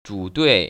[zhŭduì] 주뚜이  ▶